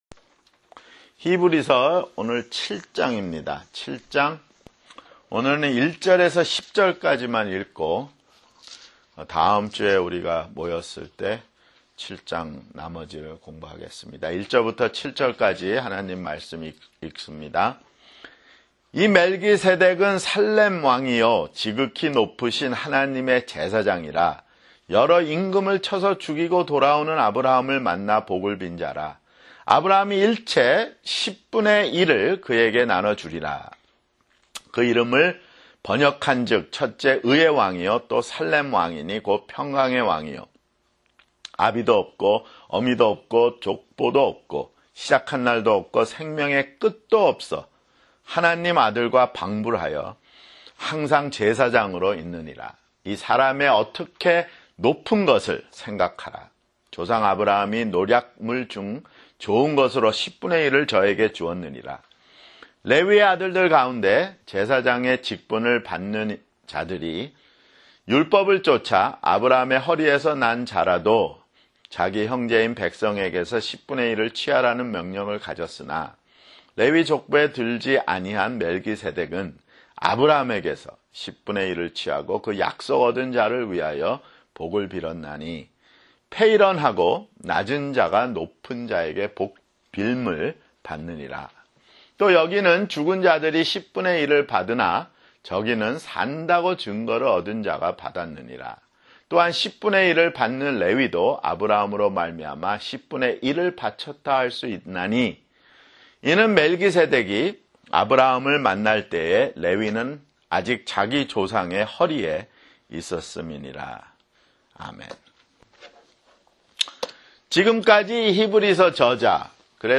[성경공부] 히브리서 (25)